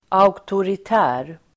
Ladda ner uttalet
Uttal: [a_oktorit'ä:r]